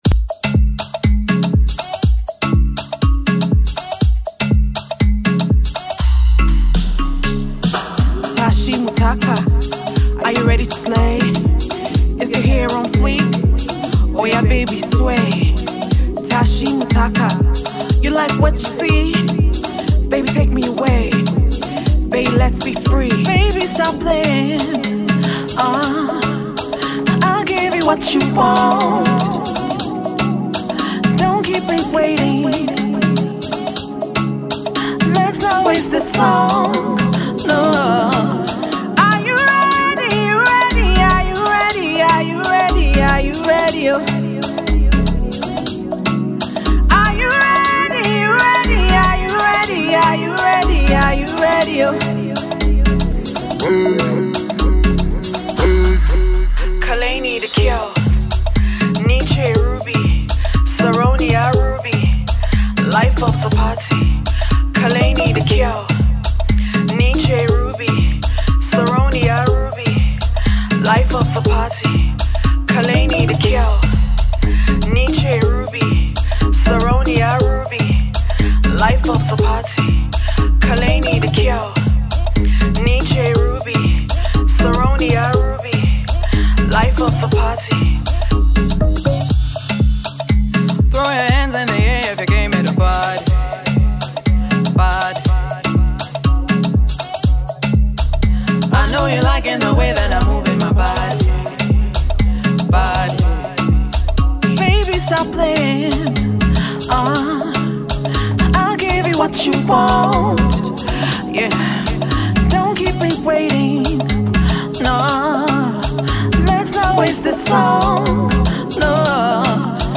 a sizzling hot, dance-driven song
snazzy upbeat